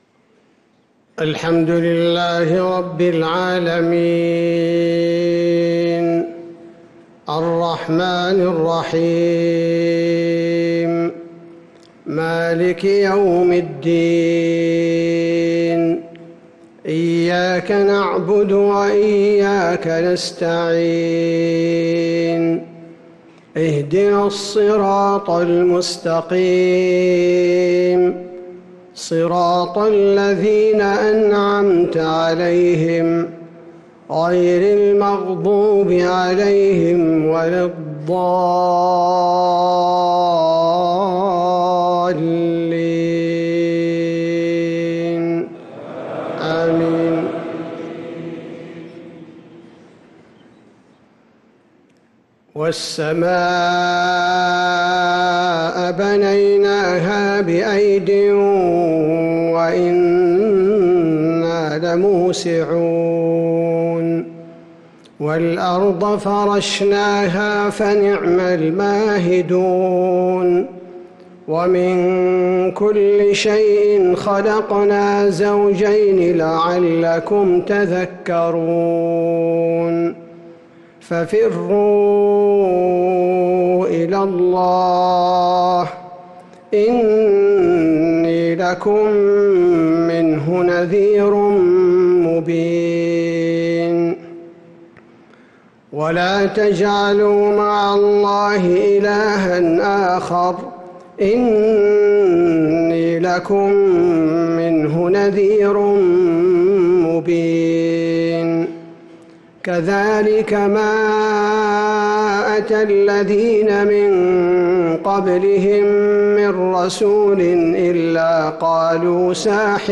صلاة المغرب ٩-٢-١٤٤٧هـ | خواتيم سورة الذاريات 47-60 | Maghrib prayer from Surah ad-Dhariyat | 3-8-2025 > 1447 🕌 > الفروض - تلاوات الحرمين